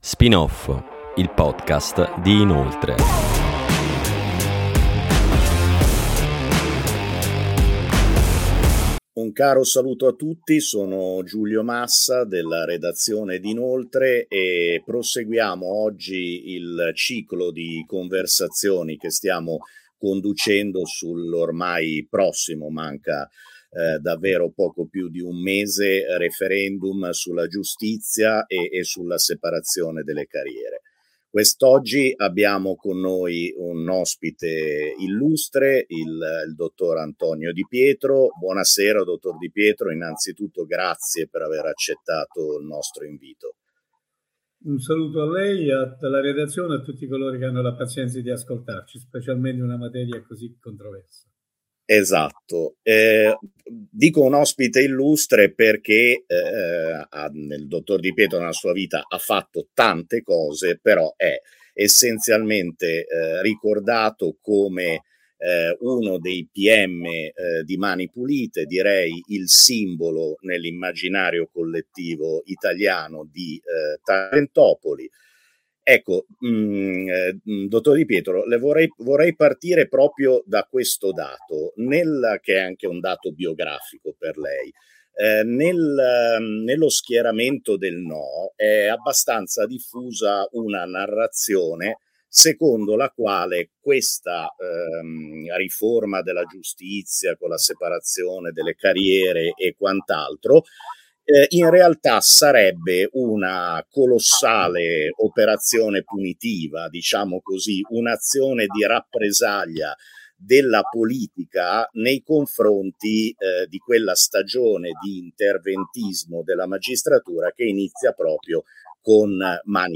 Ep. 2: Un voto informato contro i falsi argomenti. Intervista a Antonio Di Pietro